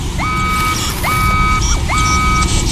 • ROBOT SHIP ALARM.wav
ROBOT_SHIP_ALARM_n9d.wav